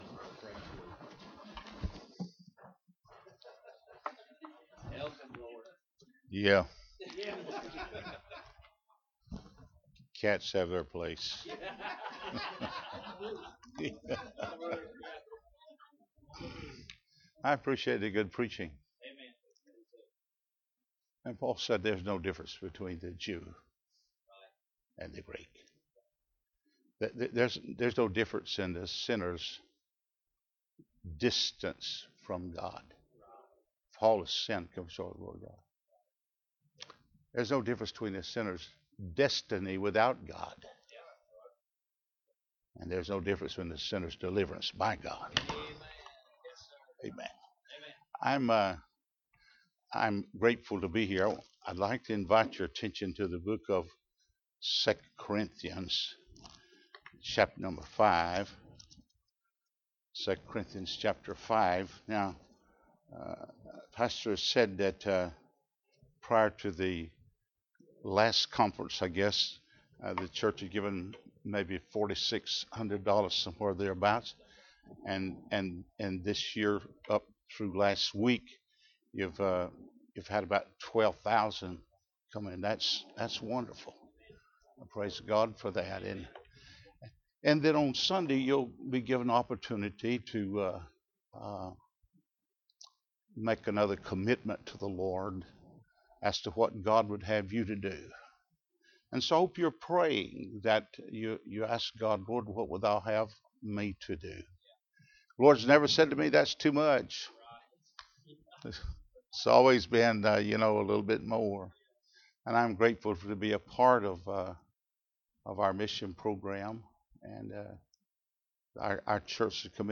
2 Corinthians 5:14-21 Service Type: Mission Conference Bible Text